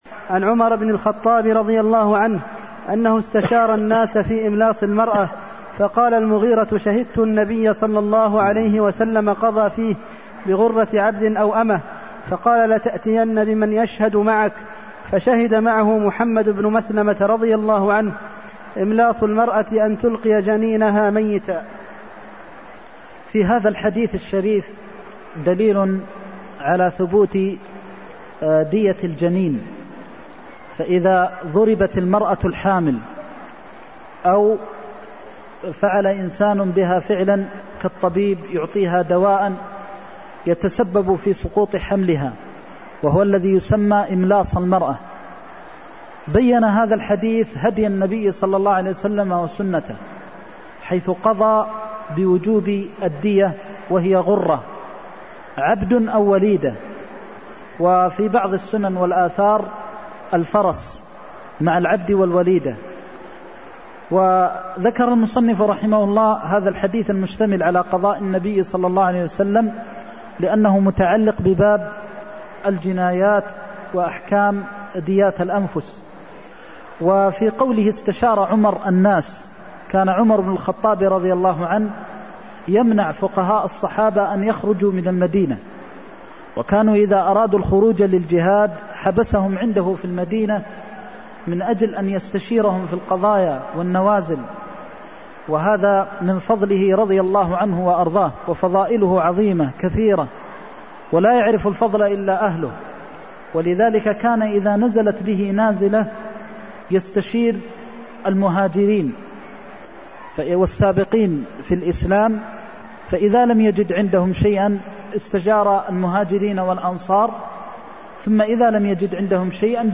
المكان: المسجد النبوي الشيخ: فضيلة الشيخ د. محمد بن محمد المختار فضيلة الشيخ د. محمد بن محمد المختار قضى فيه بغرة عبد أو أمة (324)